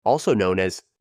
No inglês, a sigla é falada pronunciando as letras mesmo ou também falando o que ela significa.